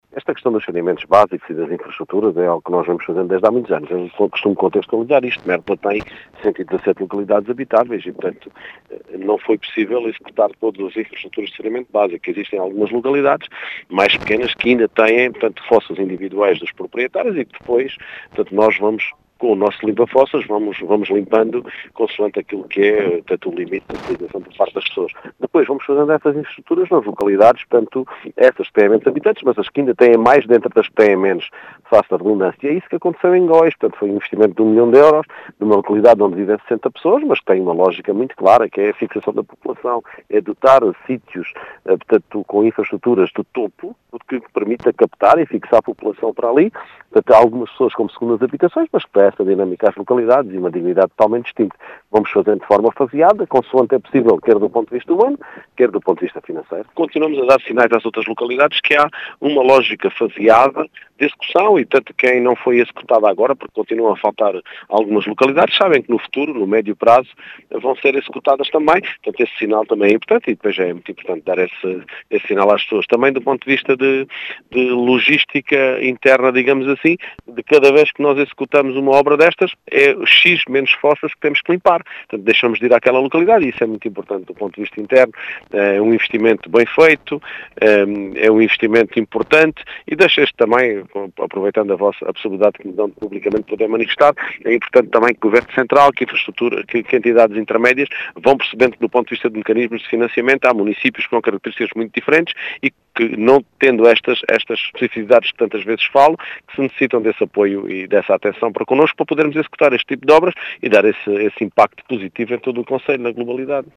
As explicações são do presidente da Câmara de Mértola, Mário Tomé, que realça a importância desta intervenção que atingiu o milhão de euros.